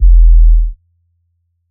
DB - Kick (16).wav